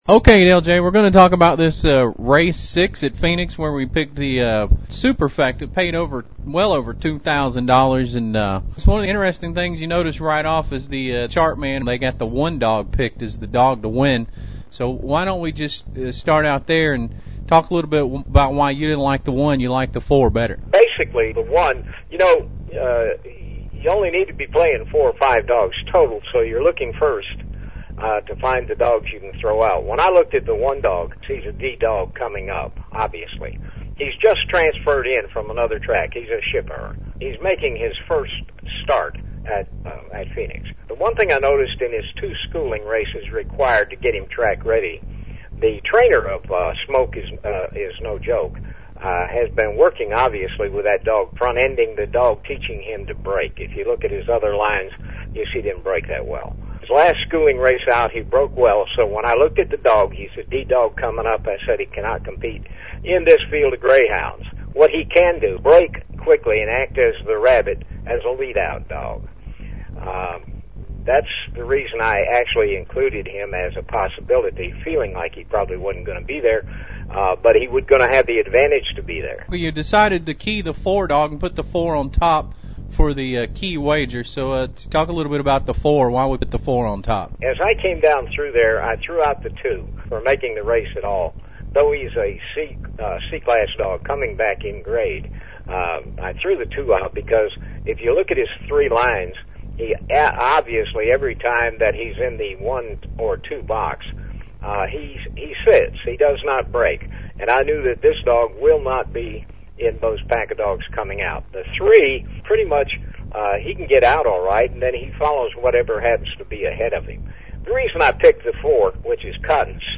Lesson 1